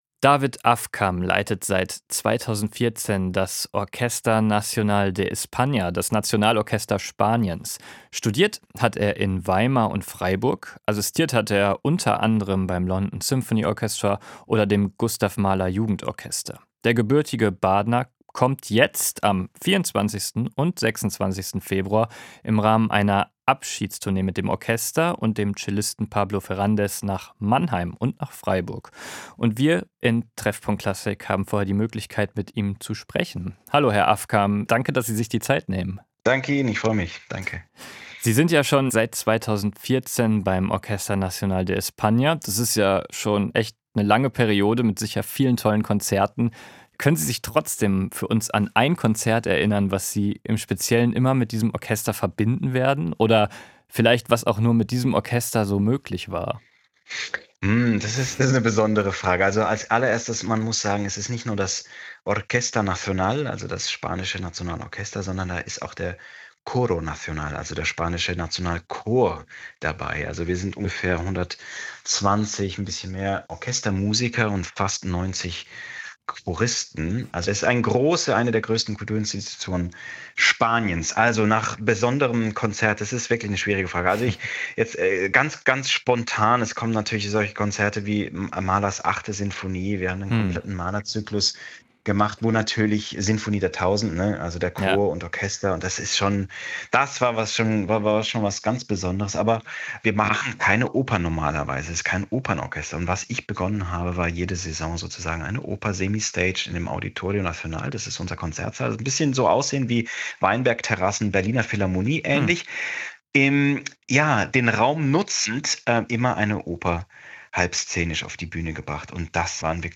Musikgespräch